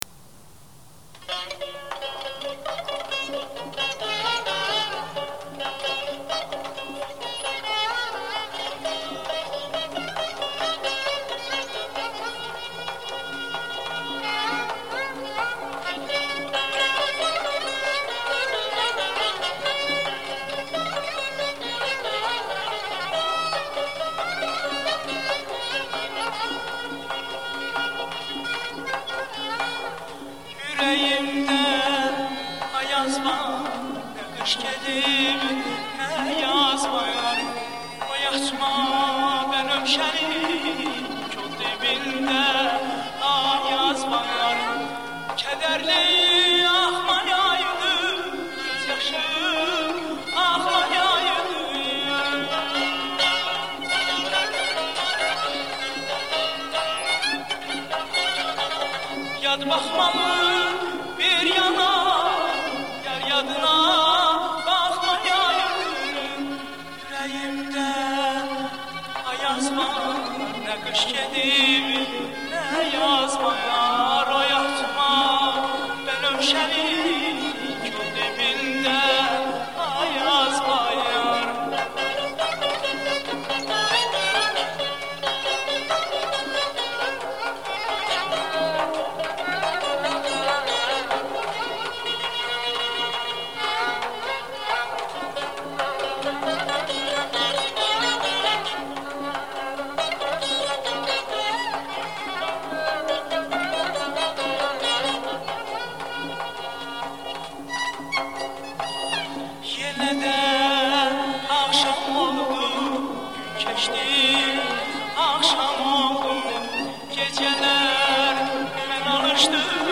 xalq mahnılarda çox gözəl bir mahnə